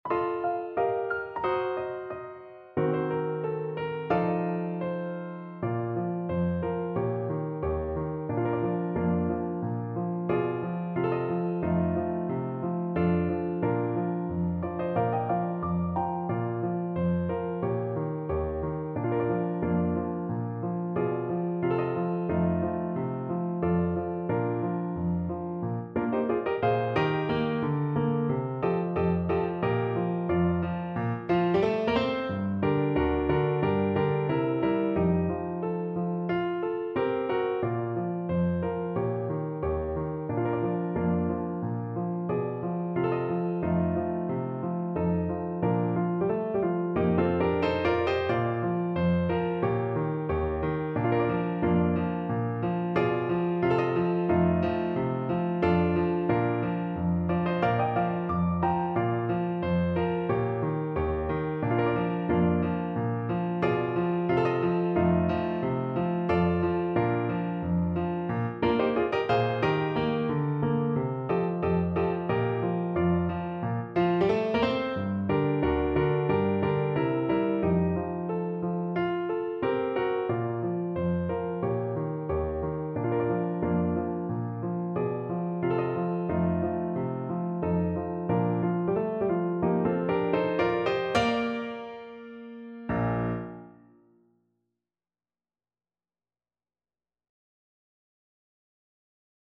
Clarinet version
Grazioso =90
2/2 (View more 2/2 Music)
F5-G6